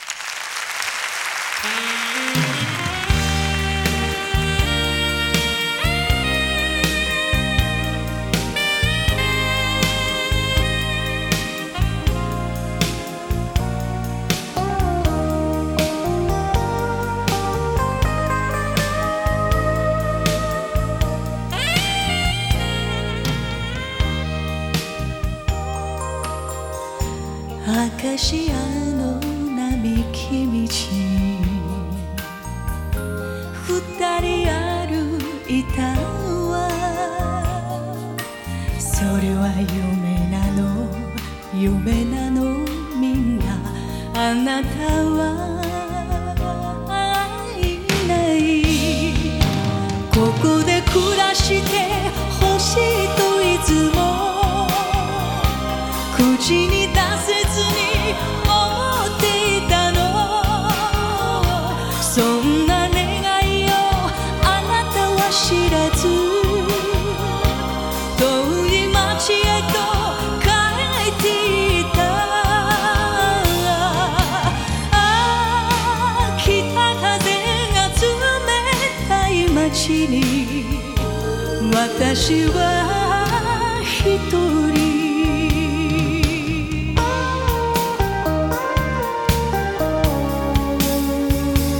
ジャンル: Japanese Pop